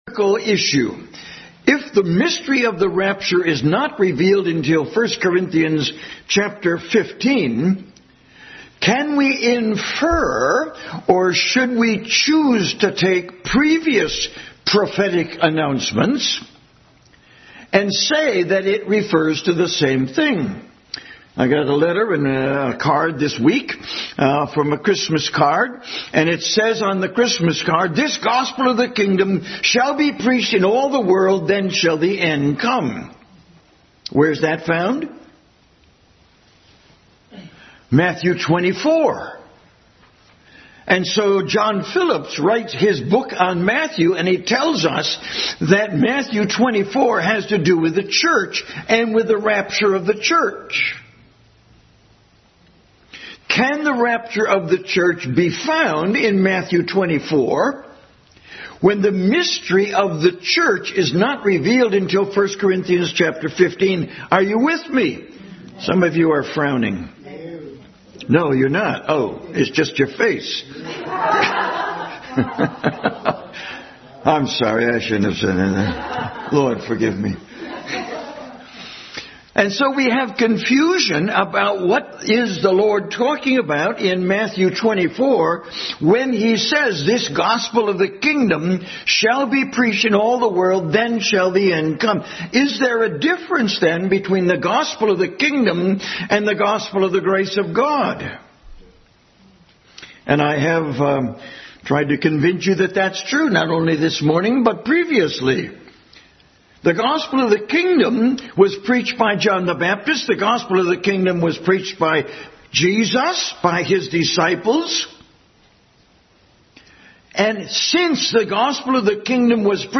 Adult Sunday School Class continued study in 1 Corinthians.
1 Corinthians 15:50-58 Service Type: Sunday School Adult Sunday School Class continued study in 1 Corinthians.